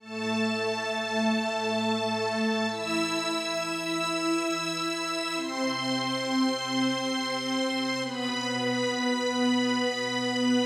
描述：ynth 字符串
Tag: 90 bpm Hip Hop Loops Strings Loops 1.79 MB wav Key : Unknown